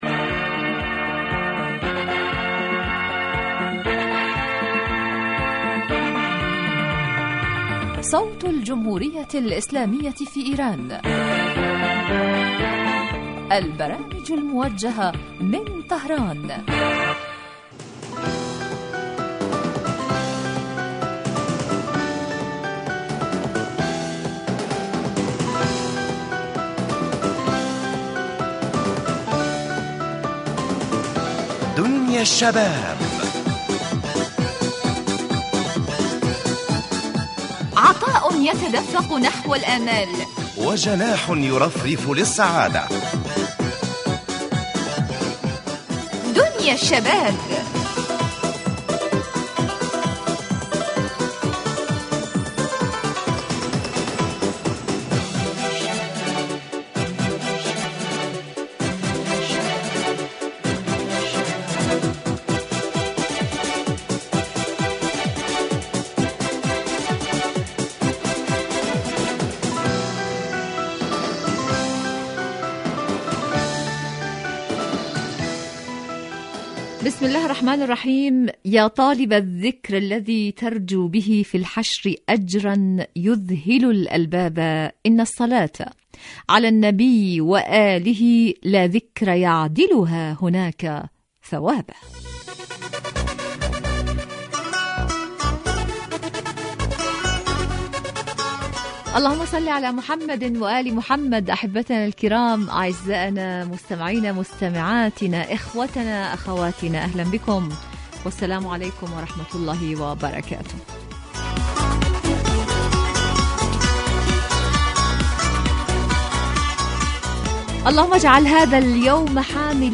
برنامج اجتماعي غني بما یستهوی الشباب من البلدان العربیة من مواضیع مجدیة و منوعة و خاصة ما یتعلق بقضایاهم الاجتماعیة وهواجسهم بالتحلیل والدراسة مباشرة علی الهواء